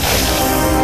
Giant_Anaconda_Roar.ogg